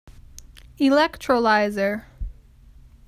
(ĭ-lĕktrə-līzər)